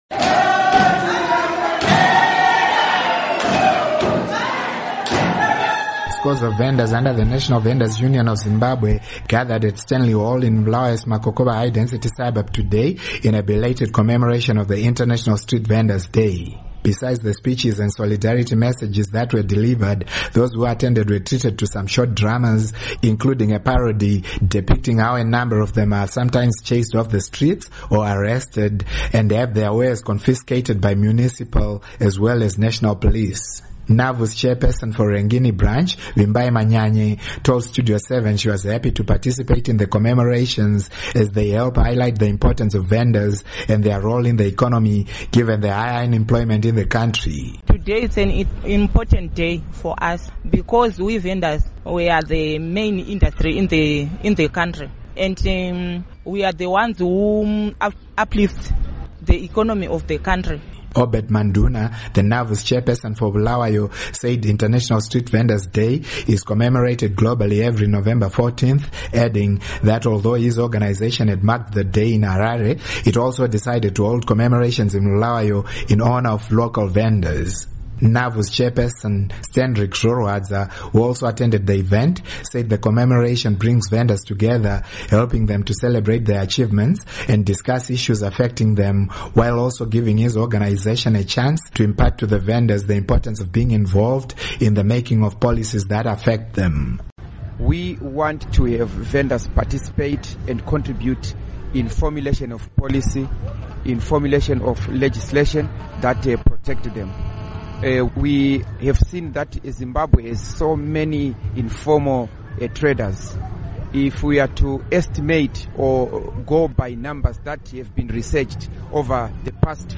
Report on Vendors